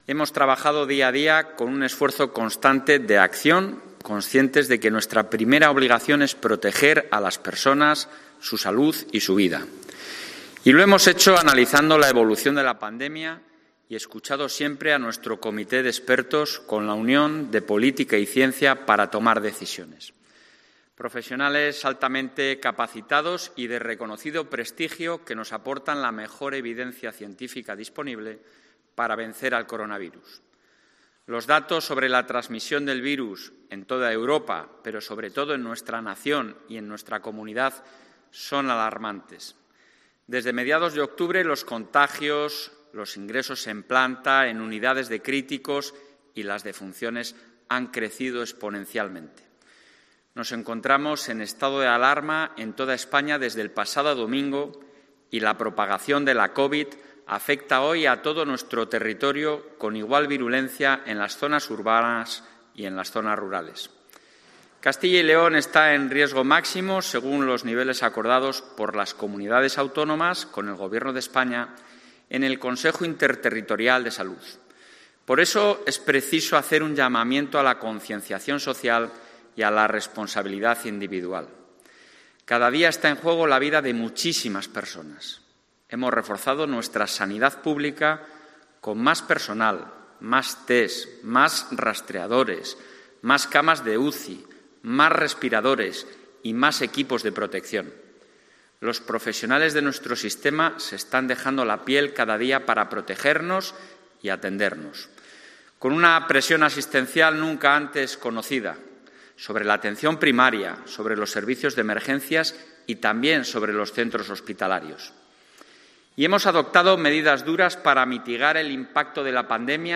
Declaración institucional del presidente de la Junta de CyL